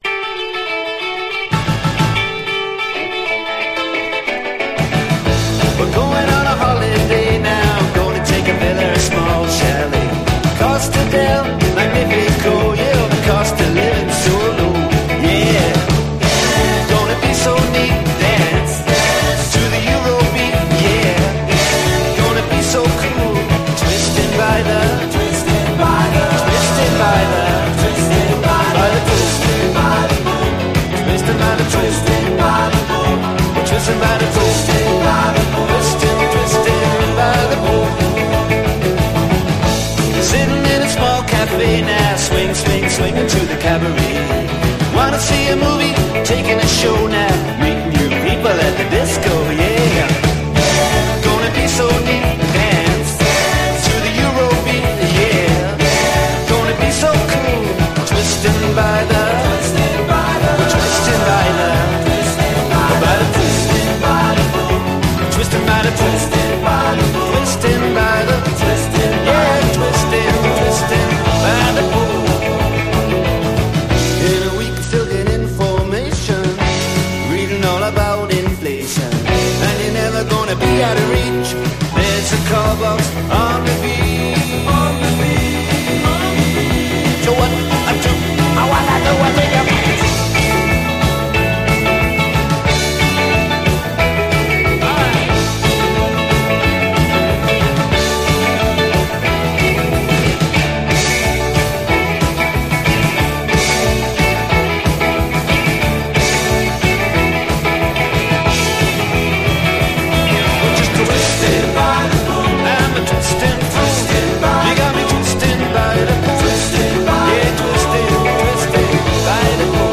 80's～ ROCK, ROCK
80’Sサーフ・ネオロカ・チューン！トロピカル度満点の爽快パーティー・チューン！